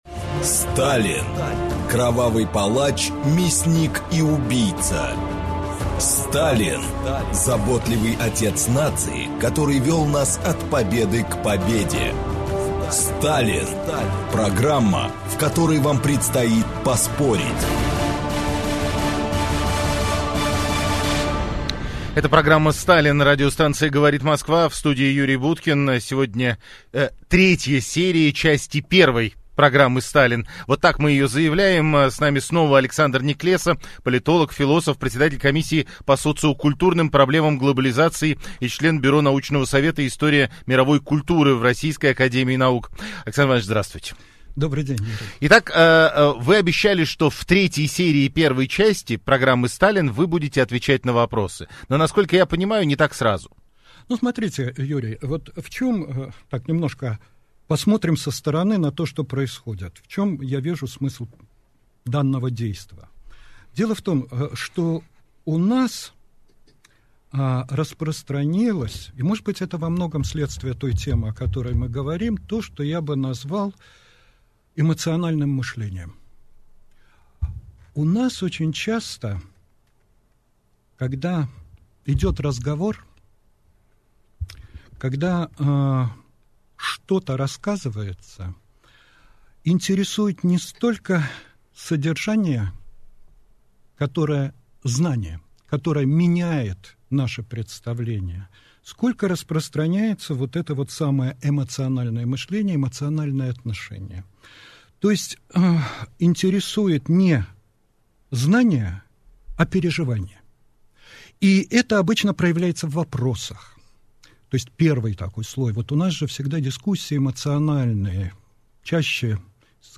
Аудиокнига Феномен Сталина в русском сознании. Часть 3 | Библиотека аудиокниг